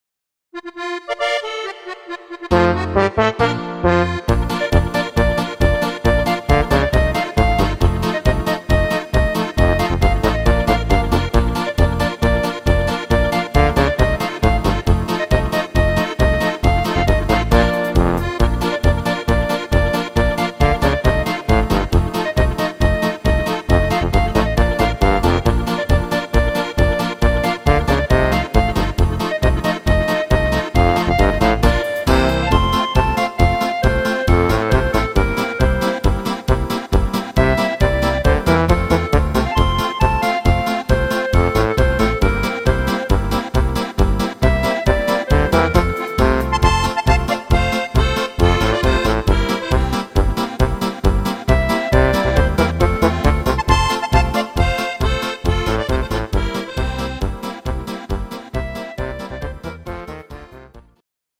Rhythmus  Polka
Art  Instrumental Allerlei